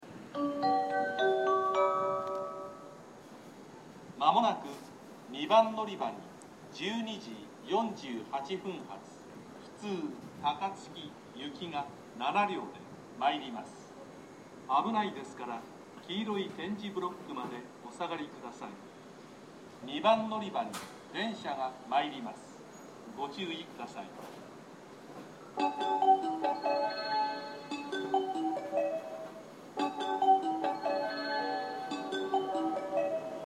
音量もほかの駅と比べて大きめ ですね。
２番のりばA：JR神戸線
接近放送普通　高槻行き接近放送です。